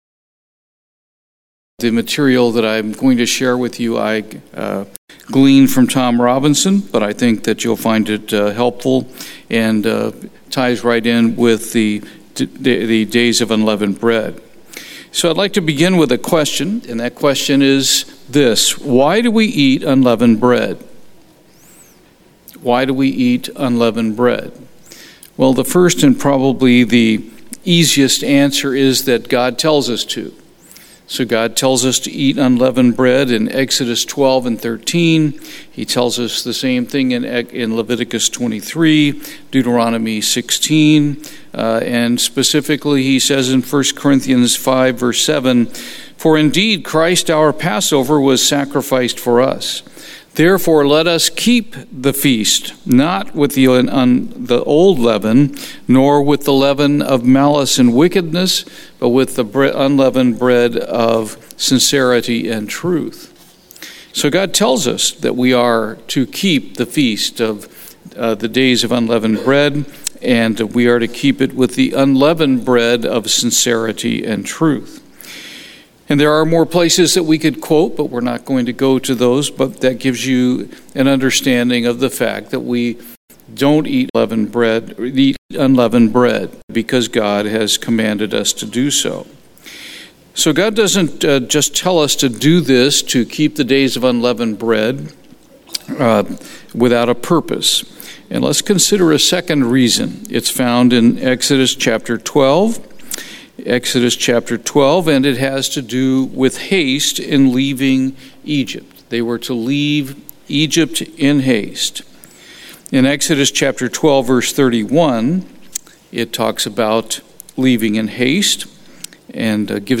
Given in Houston, TX
holy day sermon